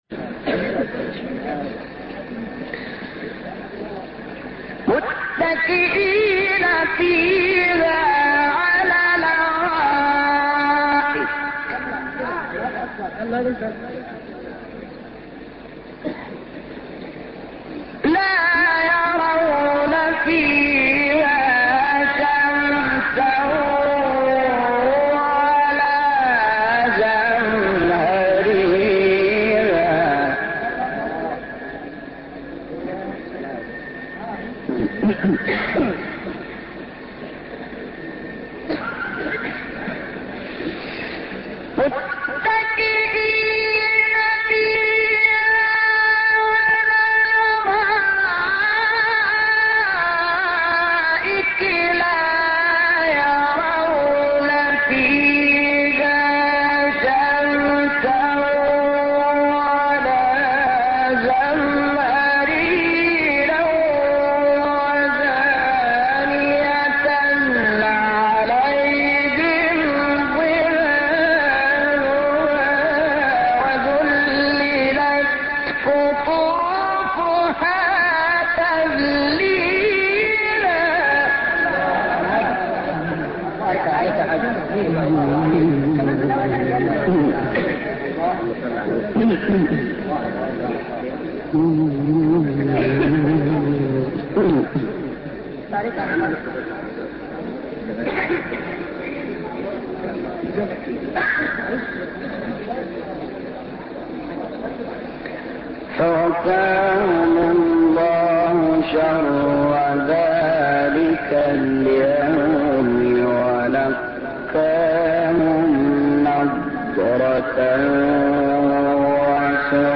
شحات ـ مقام النهاوند - لحفظ الملف في مجلد خاص اضغط بالزر الأيمن هنا ثم اختر (حفظ الهدف باسم - Save Target As) واختر المكان المناسب